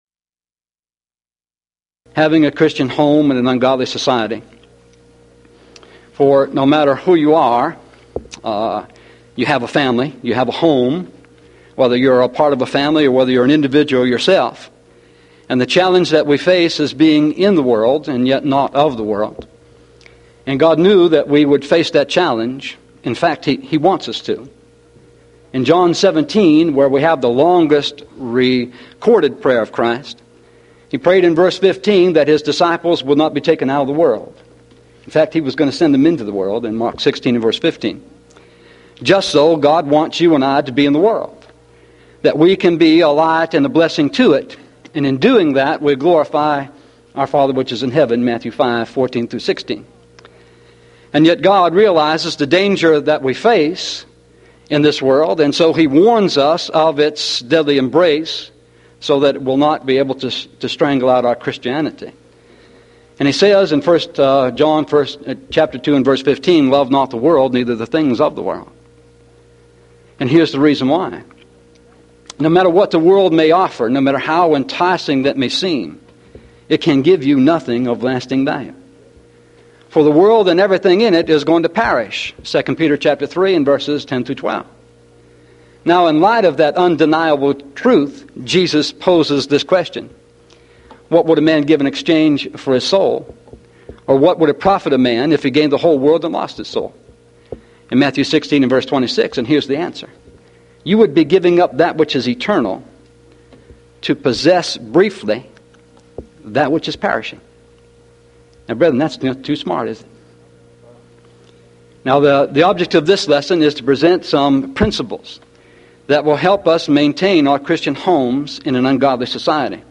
Series: Mid-West Lectures Event: 1993 Mid-West Lectures